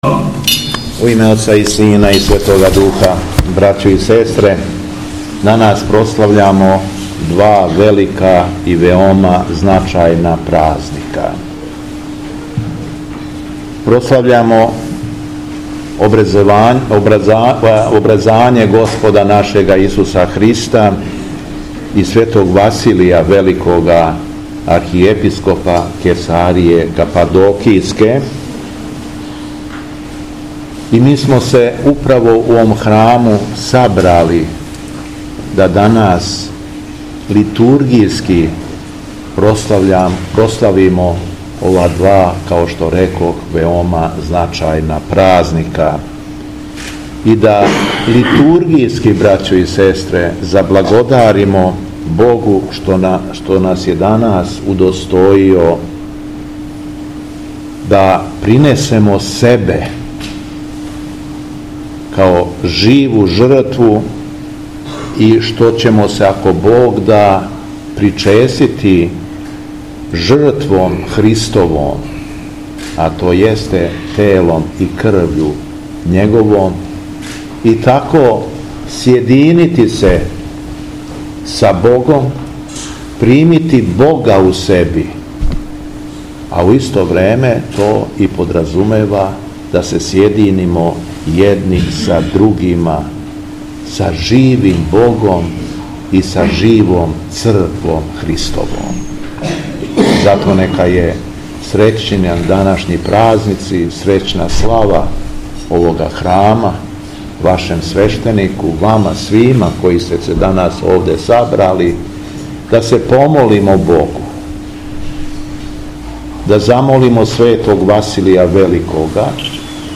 Беседа Његовог Високопреосвештенства Митрополита шумадијског г. Јована
Након прочитаног Светог Јеванђеља, окупљеним верницима се богонадахнутом беседом обратио Високопреосвећени Митрополит: